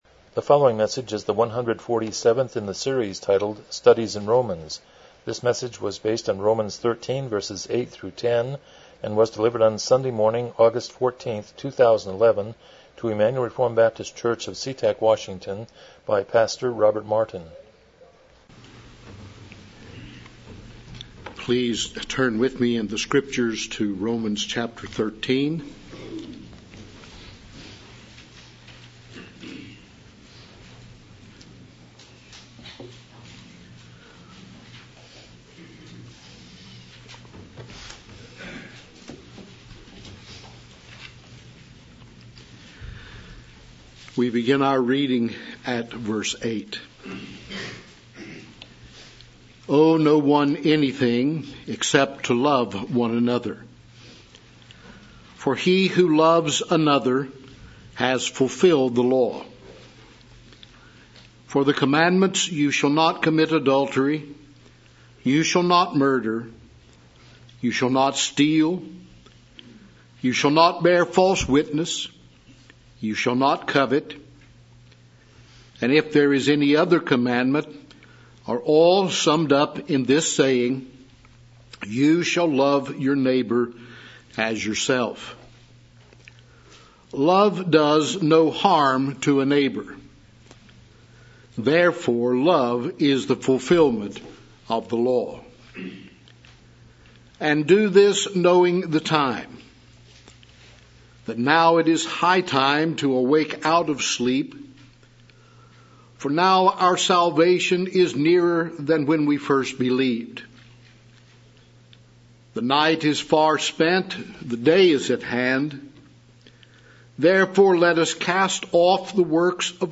Romans 13:8-10 Service Type: Morning Worship « A Centurion Comes to Christ 11 James 1:26-27